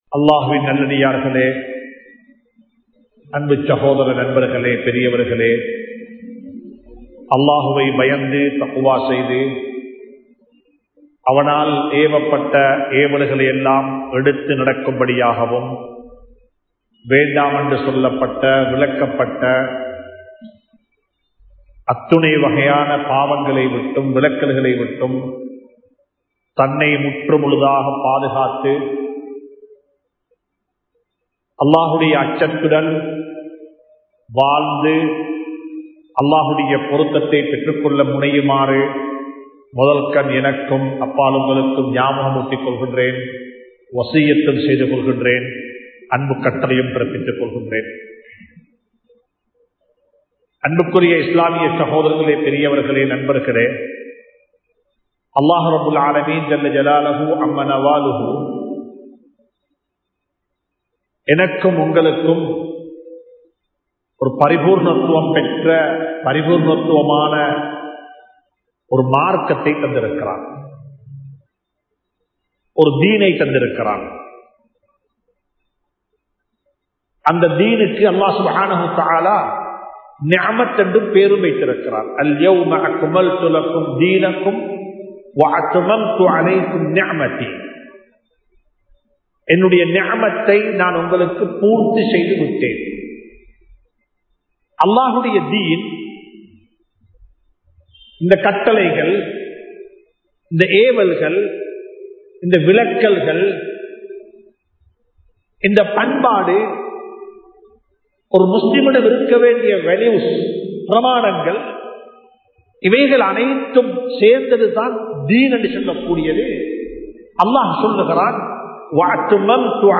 கடனில்லாமல் வாழ்வோம் (Live without debt) | Audio Bayans | All Ceylon Muslim Youth Community | Addalaichenai
Kongawela Jumua Masjidh